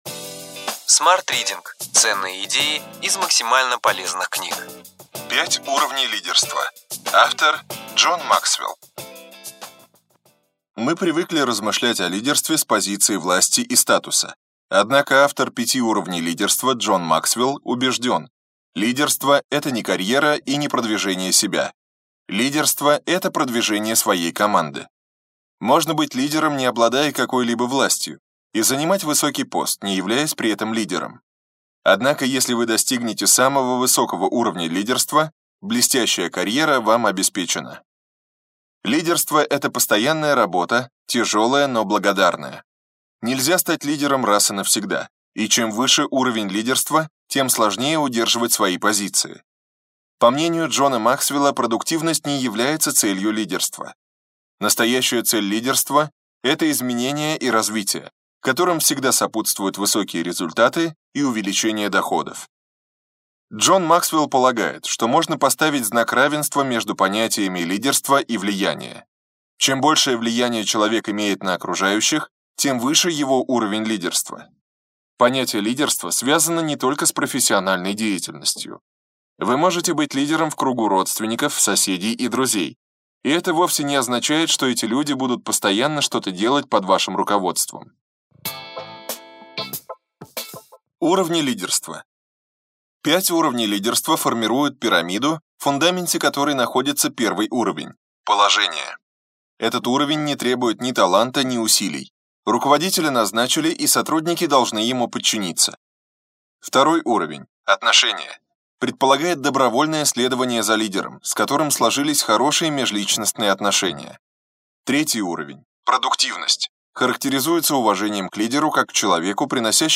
Аудиокнига Ключевые идеи книги: 5 уровней лидерства.